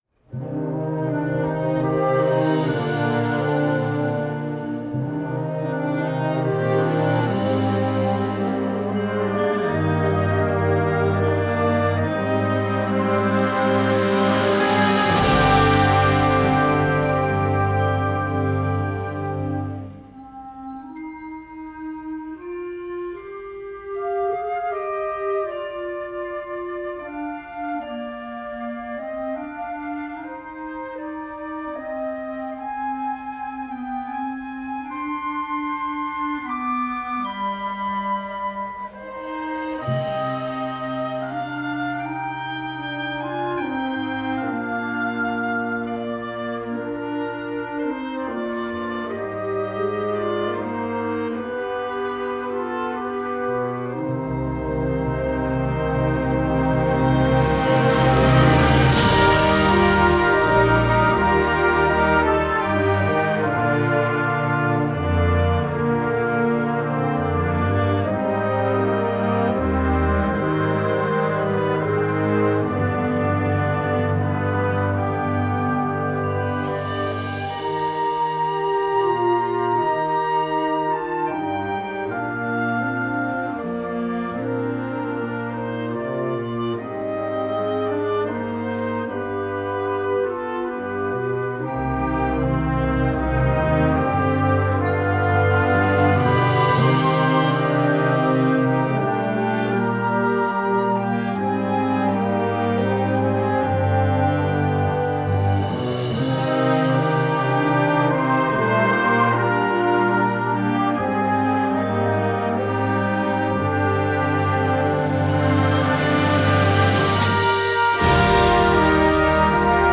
Gattung: für Jugendblasorchester
Besetzung: Blasorchester